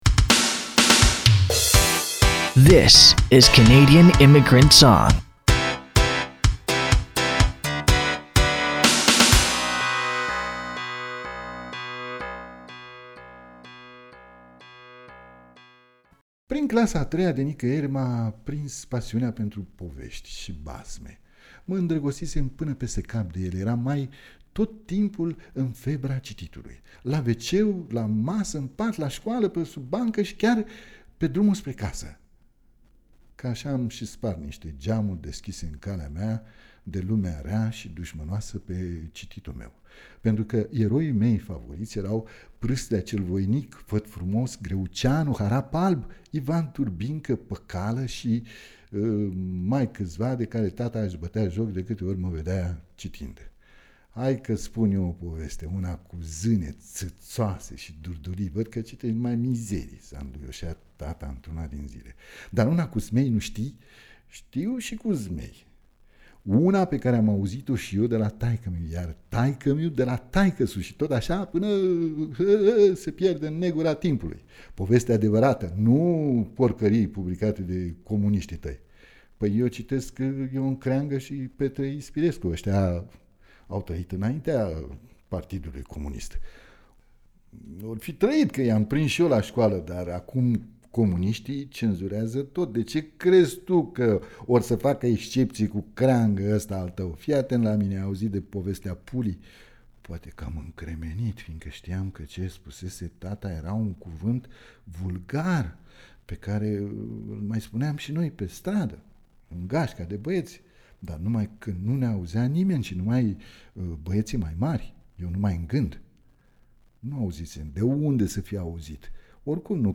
o povestire de Dorian Duma